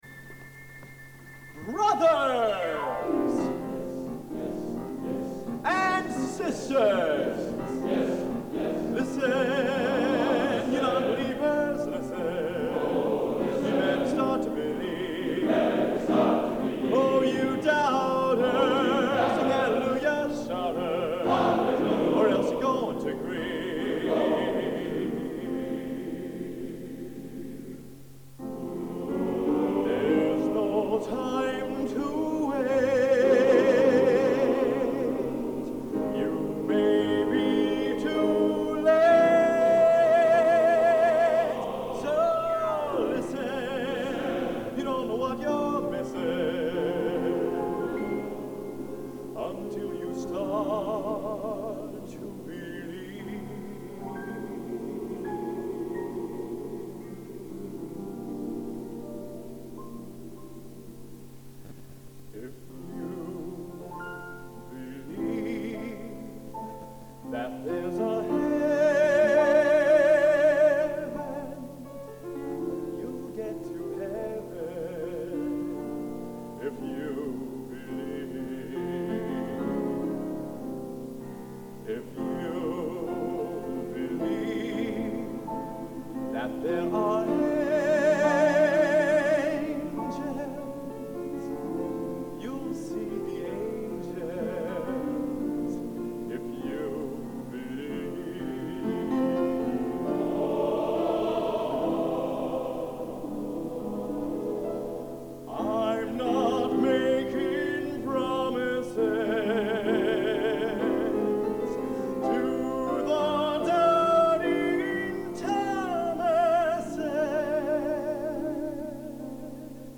Location: Old Academy of Music, Stockholm, Sweden